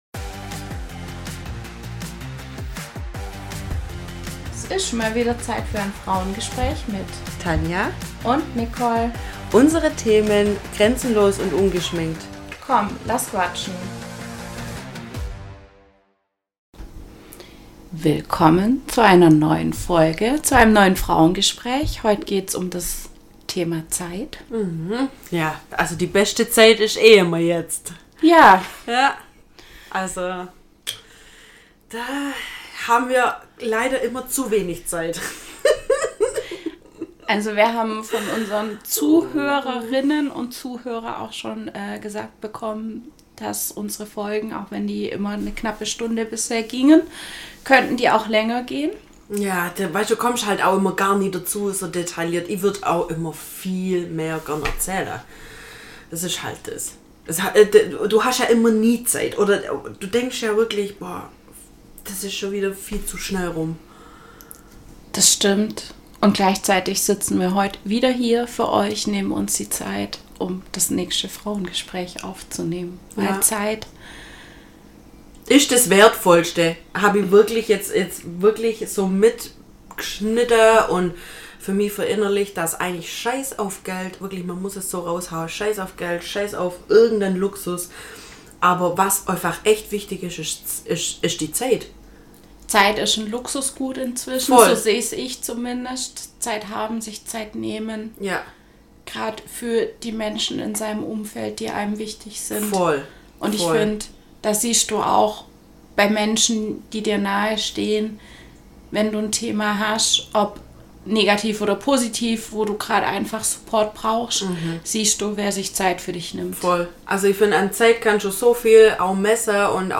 Wie haben mal wieder ein neues Frauengespräch für euch…!